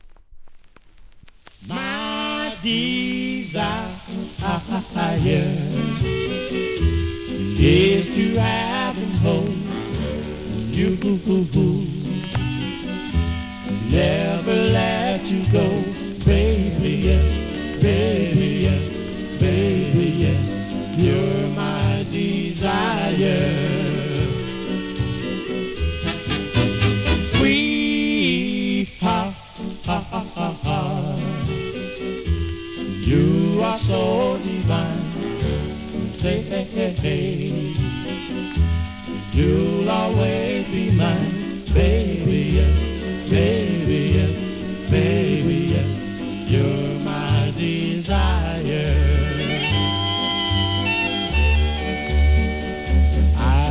SOUL/FUNK/etc... 店舗 数量 カートに入れる お気に入りに追加 '60s OLDIES!!!